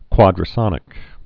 (kwŏdrə-sŏnĭk)